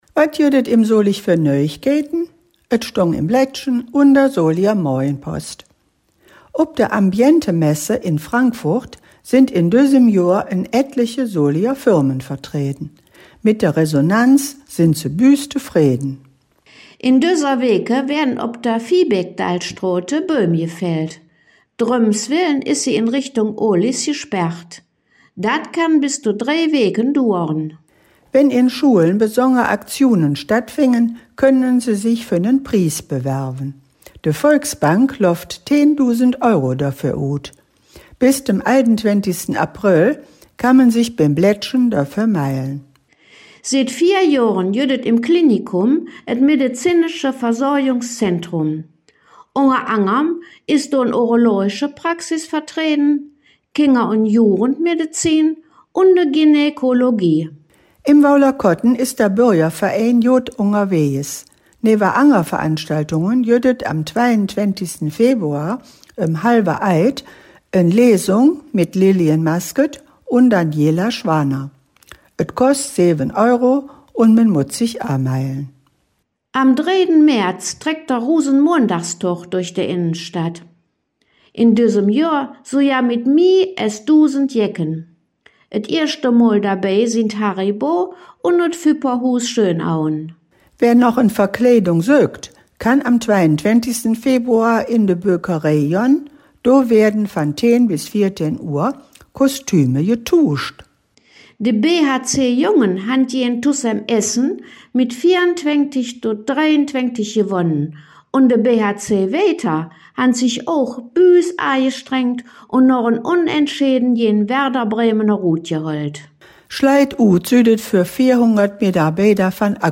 In dieser Folge "Dös Weeke em Solig" blicken de Hangkgeschmedden in Solinger Platt auf die Nachrichten vom 06. Februar 2025 bis zum 14. Februar 2025 zurück.
Von und mit der Mundartgruppe De Hangkgeschmedden.